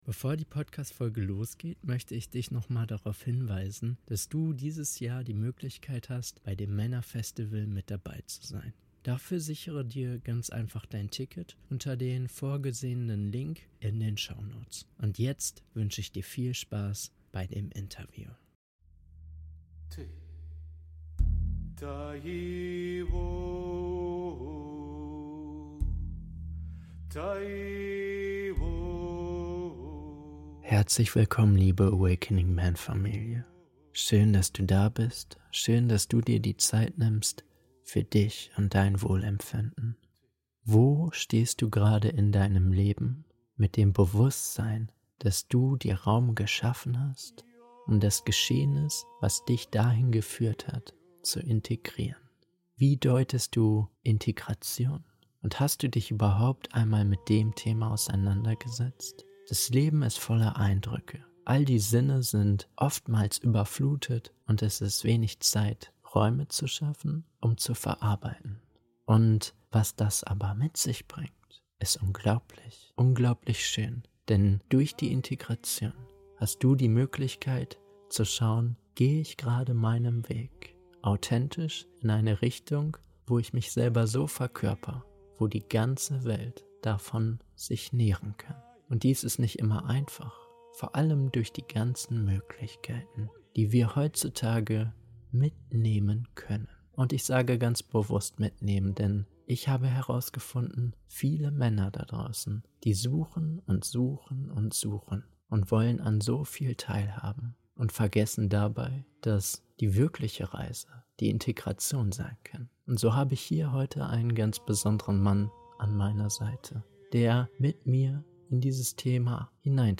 AWAKENING MEN · E69 Der Klang der Integration - Interview